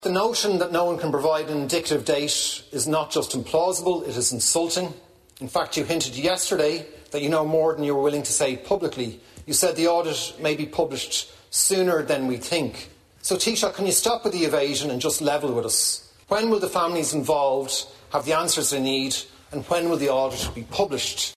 Social Democrats Deputy Leader Cian O’Callaghan, says the facts need to be known now: